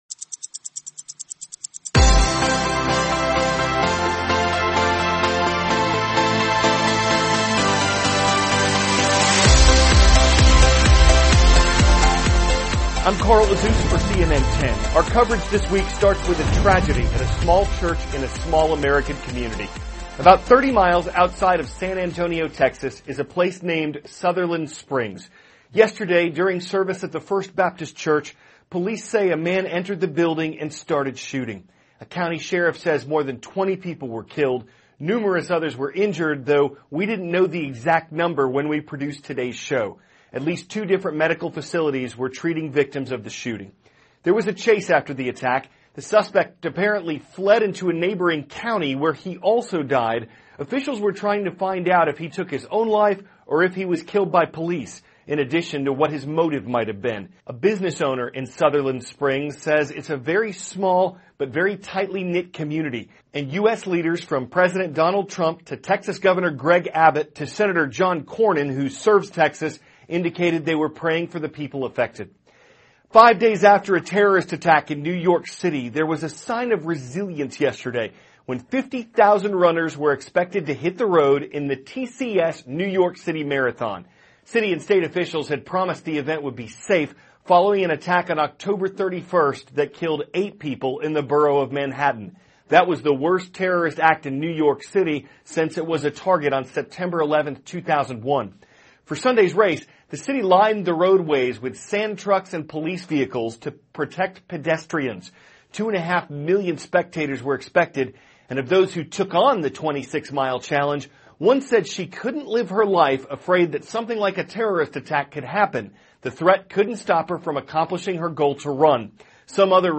CARL AZUZ, cnn 10 ANCHOR: I`m Carl Azuz for cnn 10.